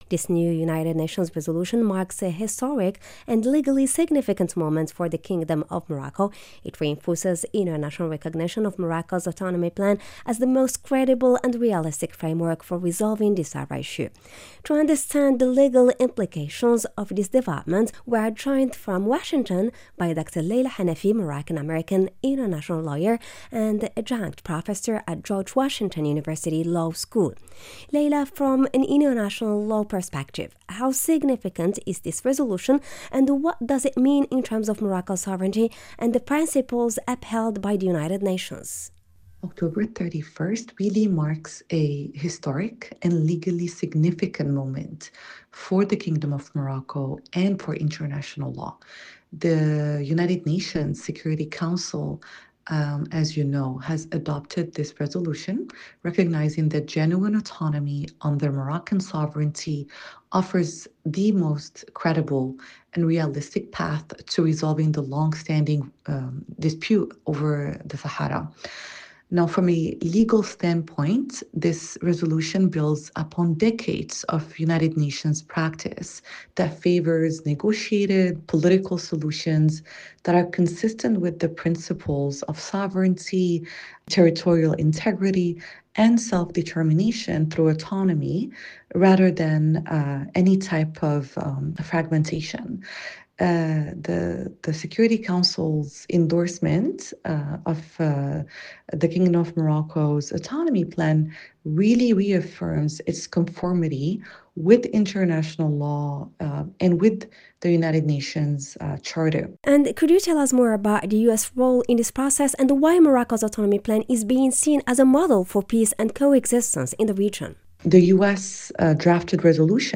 ARPA provided expert commentary to the Moroccan National Radio SNRT on the legal implications of the most recent United Nations resolution on the Moroccan Sahara.